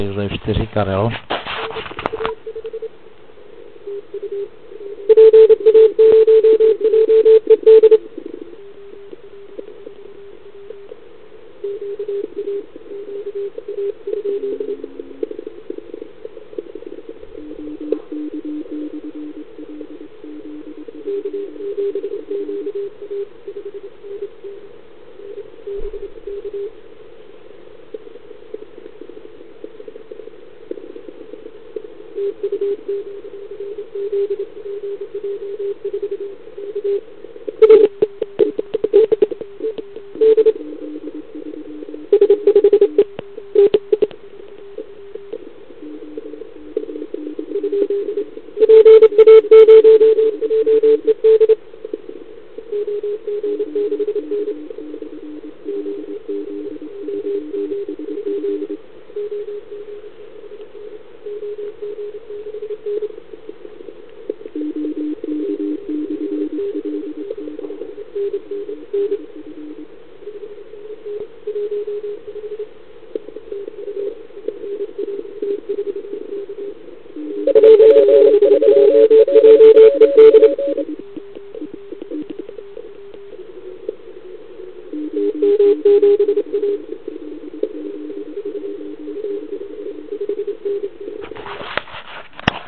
Ale pojďme se zaposlouchat do pár signálků.